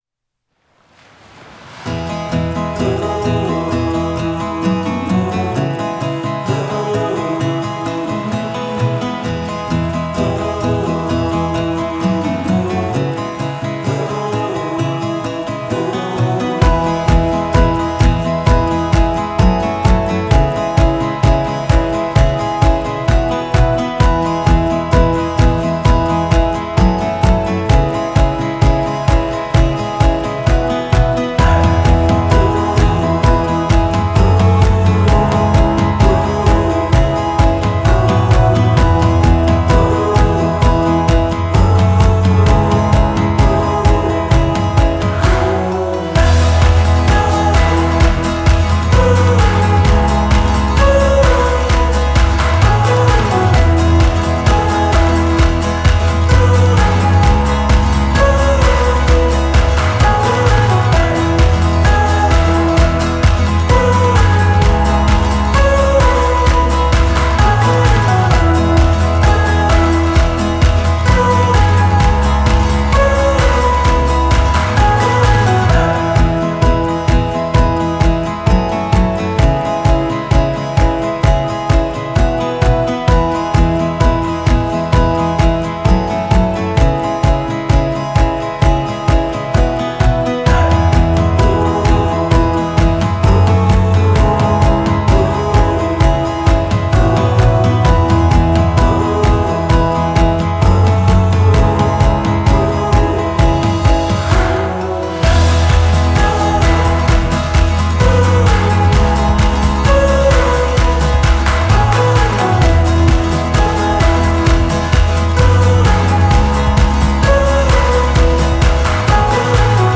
INSPIRING UPBEAT INDIE FOLK
Acoustic / Inspiring / Hopeful / Easy